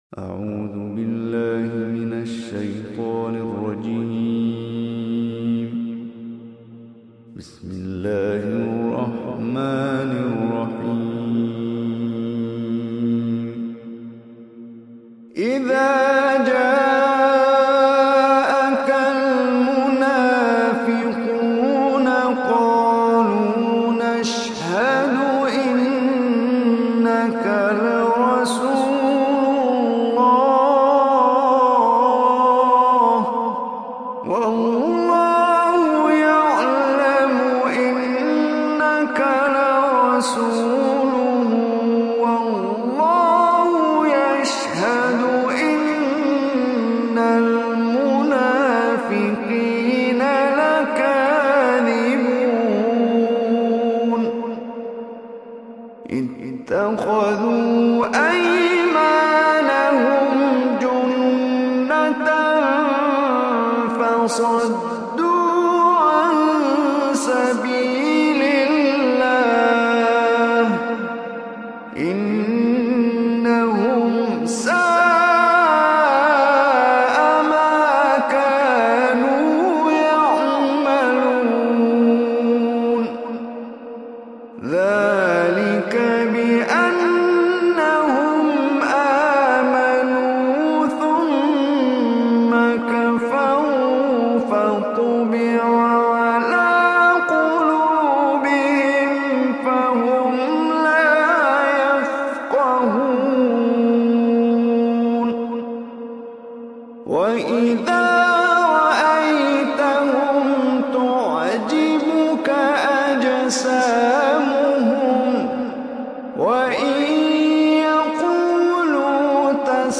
Surah Munafiqun Recitation by Omar Hisham Arabi
Surah Munafiqun, listen or play online mp3 tilawat / recitation in Arabic in the beautiful voice of Omar Hisham Al Arabi.